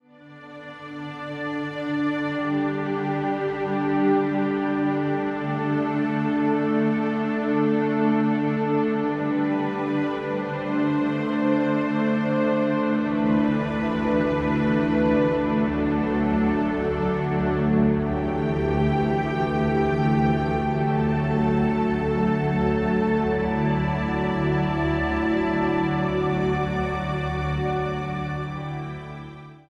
für Streichorchester
Beschreibung:Klassik; Ensemblemusik; Orchestermusik
Besetzung:Streichorchester